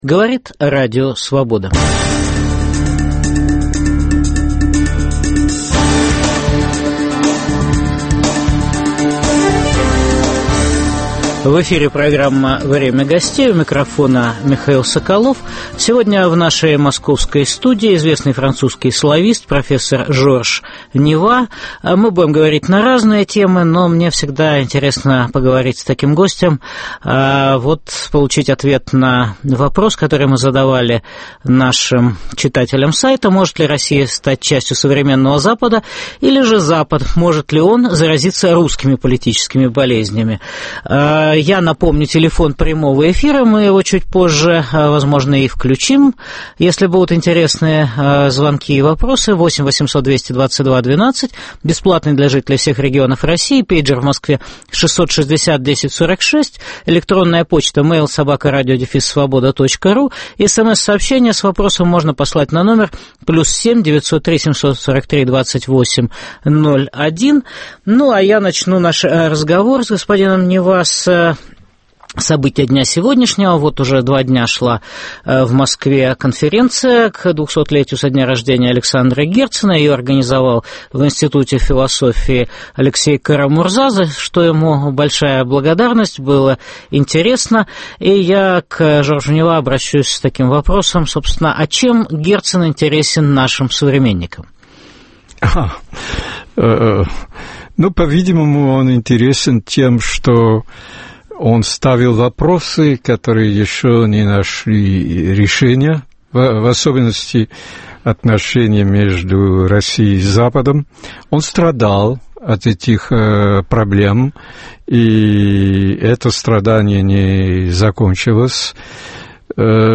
Может ли Россия стать частью современного Запада? Или же Запад заразится русскими политическими болезнями? В программе беседуем с приехавшим в Москву на конференцию к 200-летию со дня рождения Александра Герцена профессором Женевского университета, известным французским славистом Жоржем Нива.